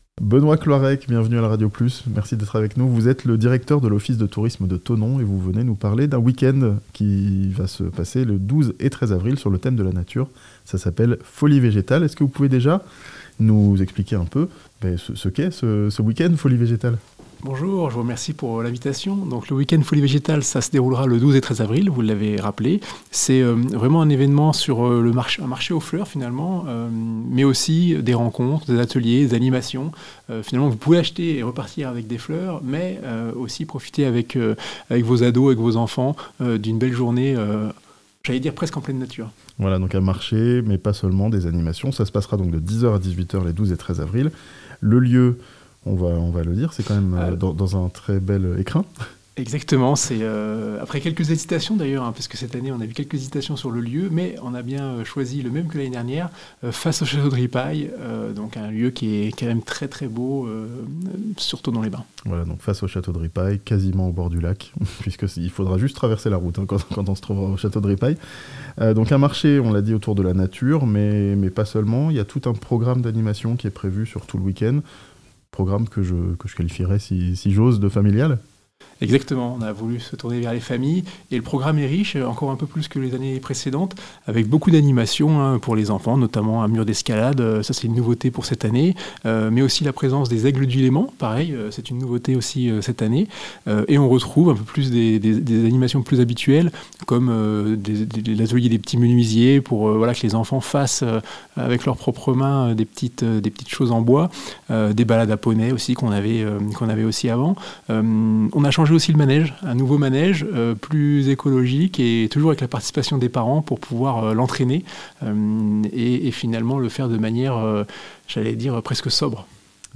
Folies Végétales, un week-end pour célébrer la nature, à Thonon (interview)